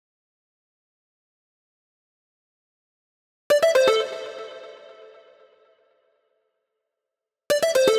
23 MonoSynth PT3.wav